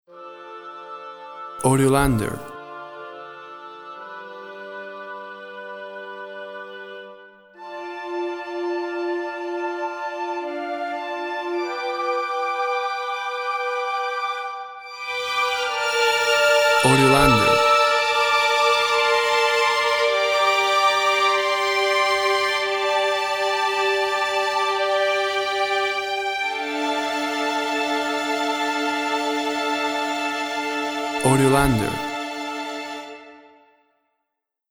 Mournful woodwinds lead to sad orchestral finish.
Tempo (BPM) 60